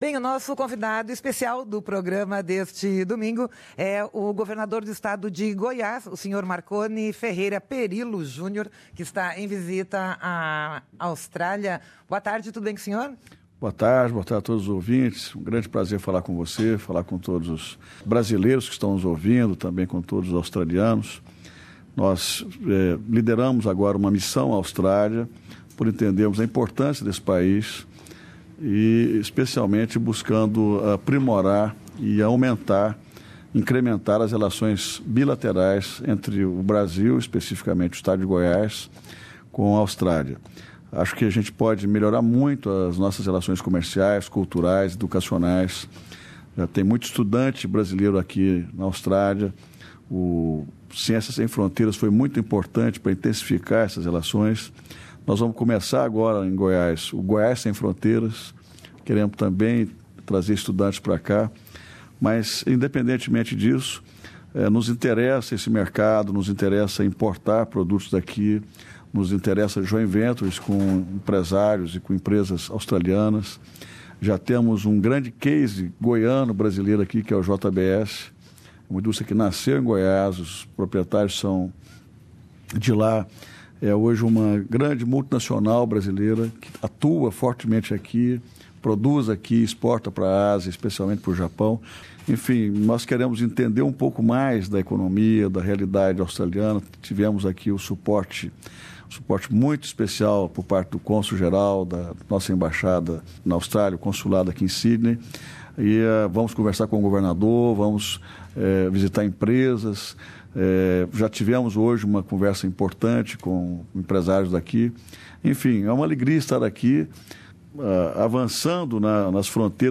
O governador de Goiás, Marconi Perillo, está em Sydney e fala ao Programa Português da Rádio SBS da pujança do estado, da política e economia brasileiras e da delegação empresarial que está liderando, em busca de novos parceiros comerciais para o seu estado.